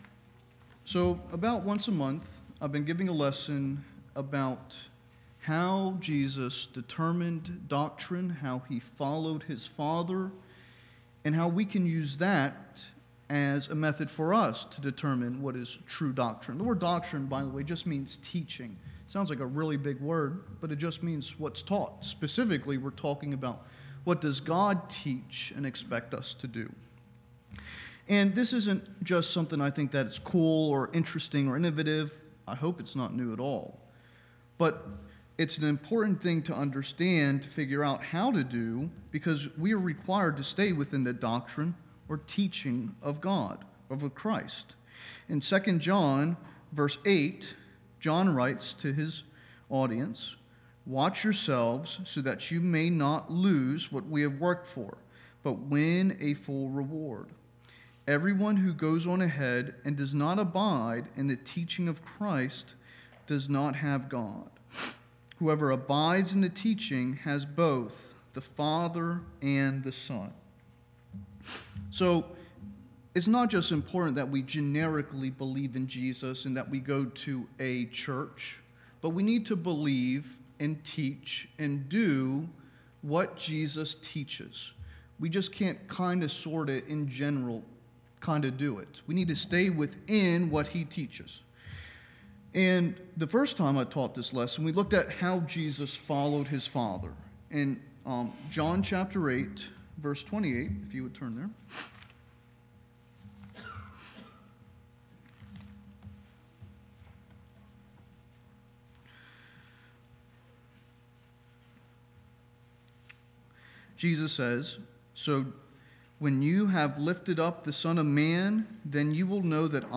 2 John 8-9 Preacher